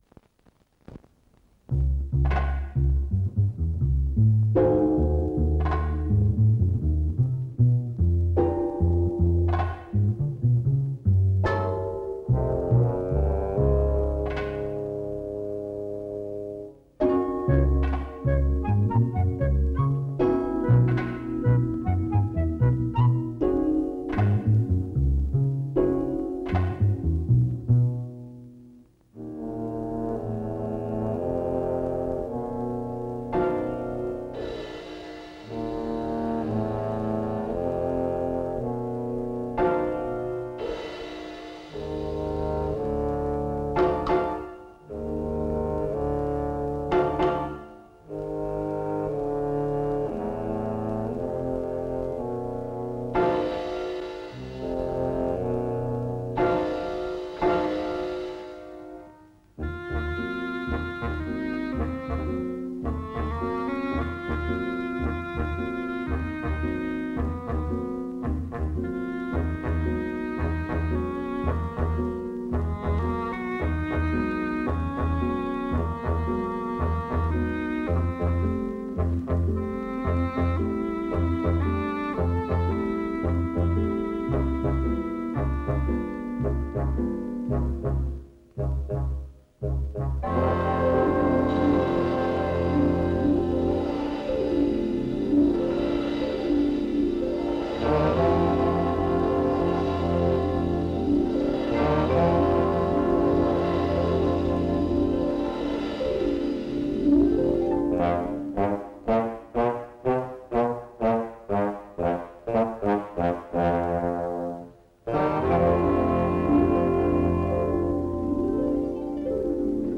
Prise de nourriture mettant en évidence le rôle des pièces et appendices spécifiques. Tourné à Roscoff. Bande sonore musicale.